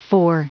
Prononciation du mot fore en anglais (fichier audio)
Prononciation du mot : fore